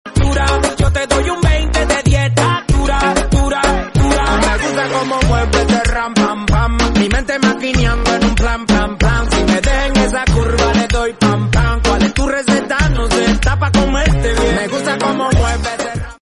hahahah sound effects free download
You Just Search Sound Effects And Download. funny sound effects on tiktok Download Sound Effect Home